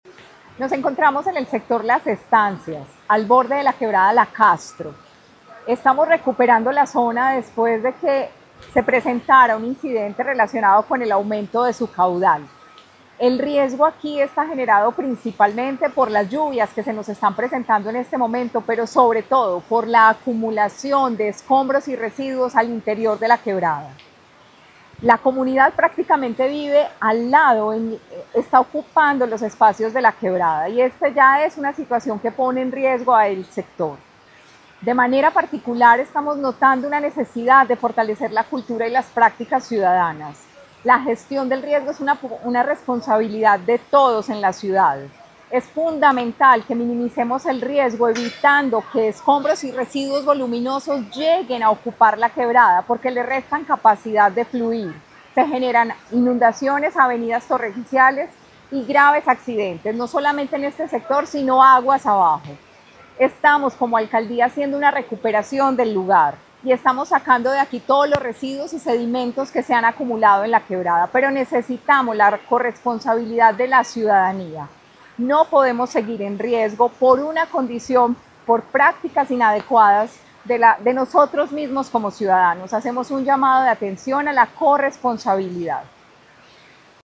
Declaraciones de la secretaria de Medio Ambiente, Marcela Ruiz
Declaraciones-de-la-secretaria-de-Medio-Ambiente-Marcela-Ruiz-1.mp3